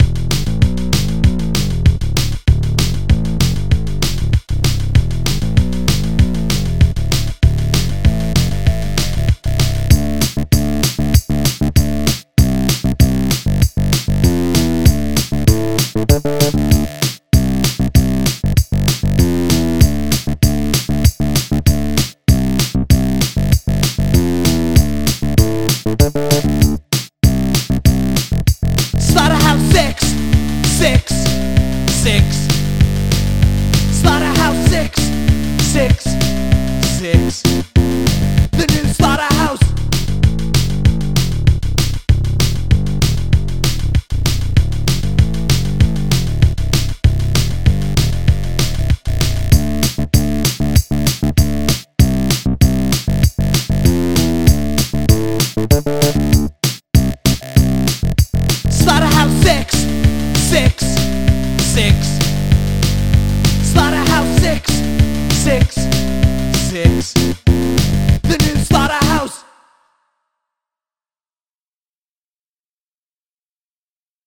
One is sort of a pop song with vocals, and one is an instrumental that samples the sound of meat (chewing, frying, cutting, pounding, grinding).
So it should be industrial (sound of technology/machinery) and synthetic (synthesized sounds).